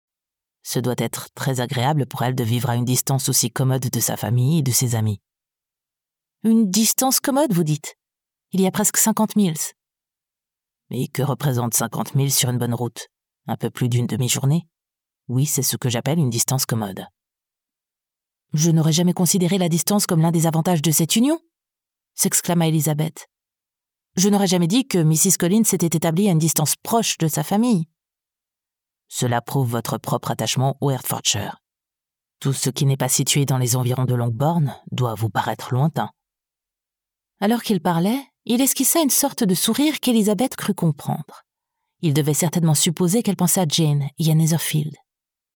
Muestras de voz nativa
Audiolibros
Microphone: NT1 RODE
MezzosopranoSoprano
DulceCalmanteSofisticadoAgradableAtractivoInteligenteSensibleAmableEnérgicoAuténticoSensualConfiadoGenuino